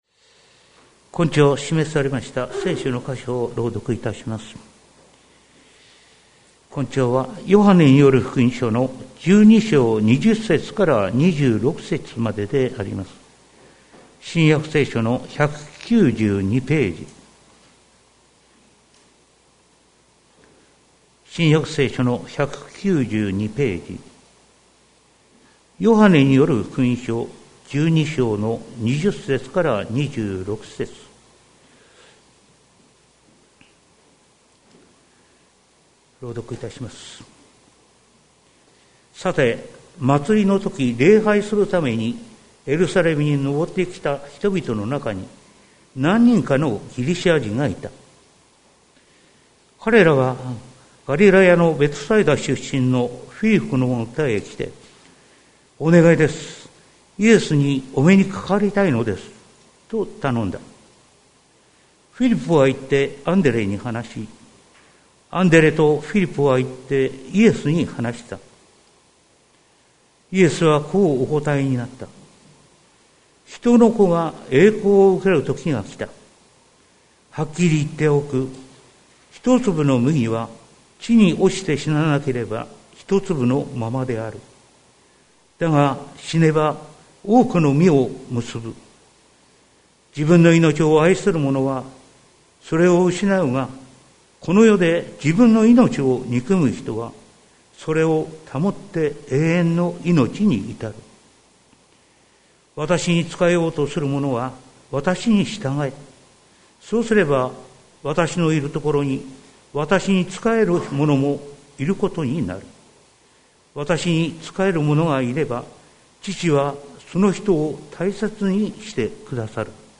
2023年03月05日朝の礼拝「一粒の麦」関キリスト教会
説教アーカイブ。